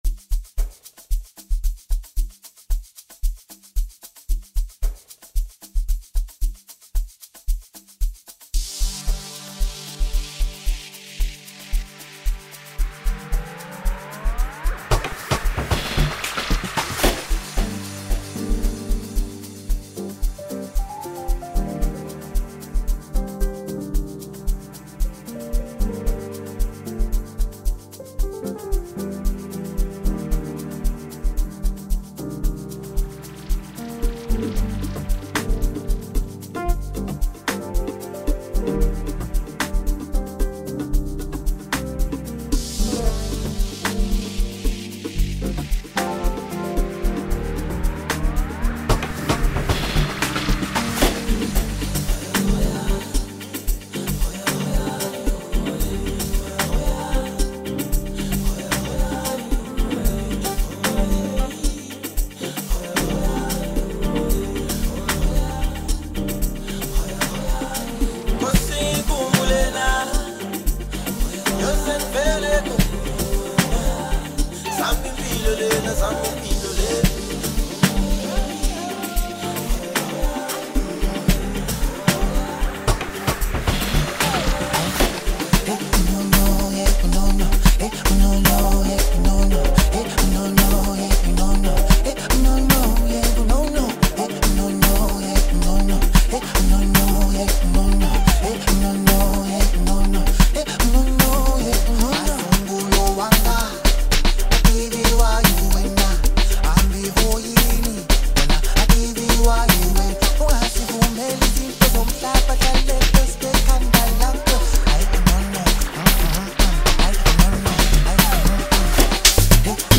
Home » South African Music
steps in with a confident verse